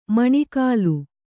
♪ maṇikāu